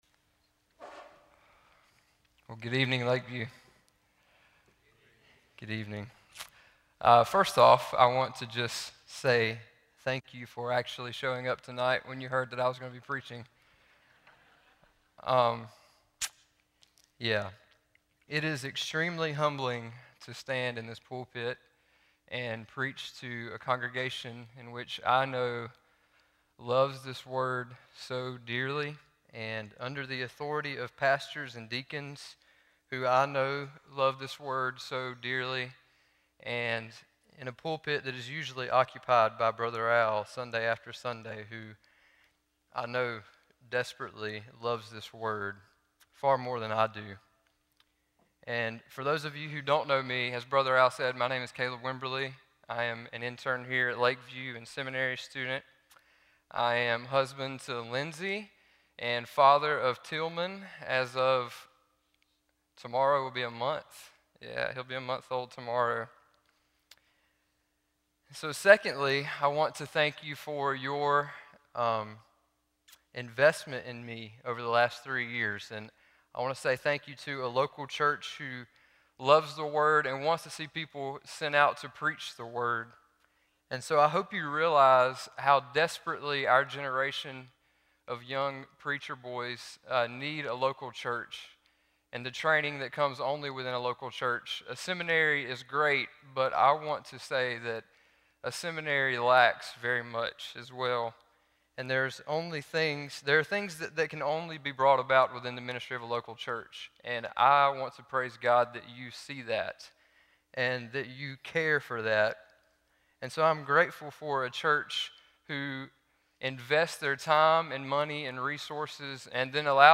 Seminary Intern Sermon Date